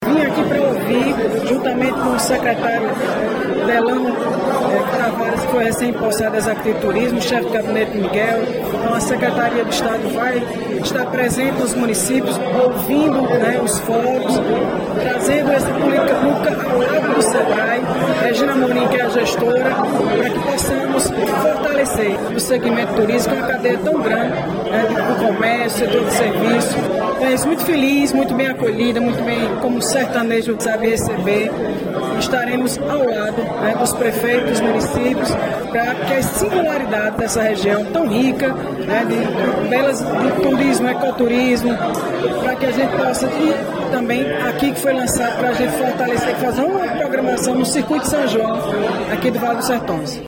Ouça Rosália Lucas  – secretária de Turismo e Desenvolvimento Econômico da Paraíba e integrante do Conselho Deliberativo Estadual (CDE) do Sebrae: